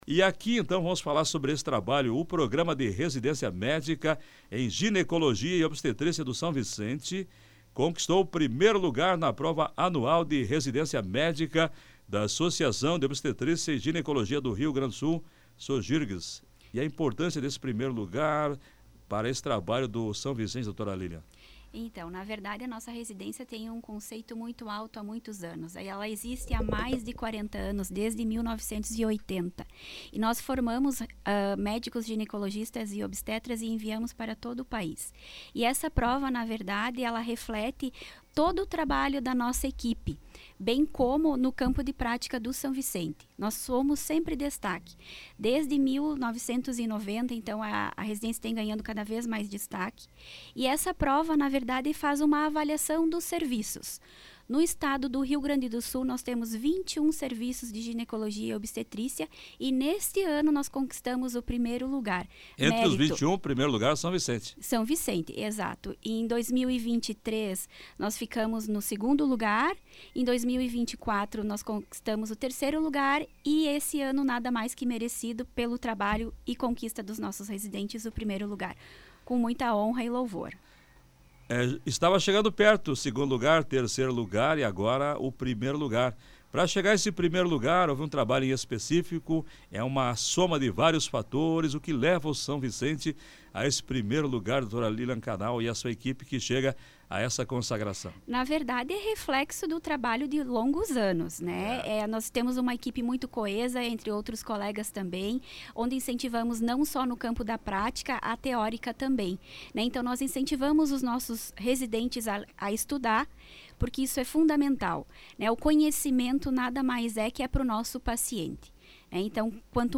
OUÇA A ENTREVISTA DA PLANALTO NEWS: https